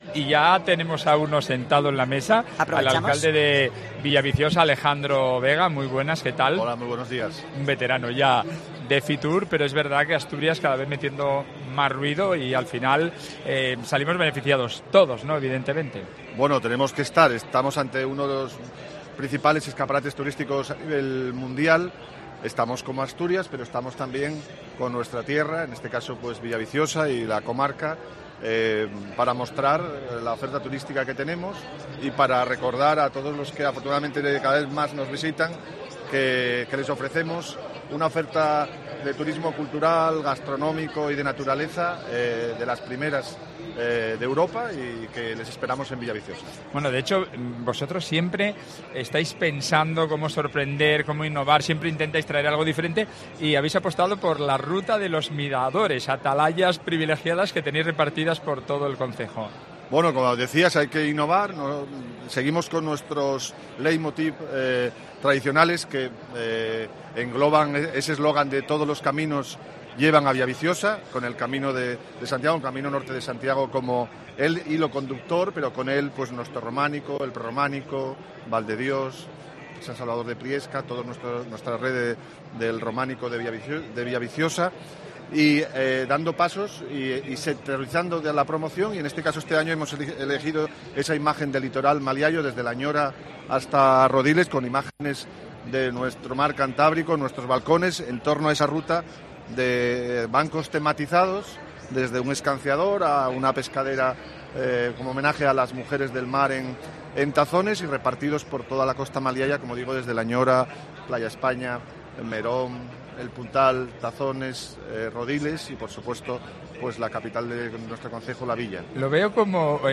El alcalde, Alejandro Vega, cuenta en COPE la nueva apuesta turística de su concejo
El alcalde de Villaviciosa explica en COPE los atractivos que el concejo promociona en FITUR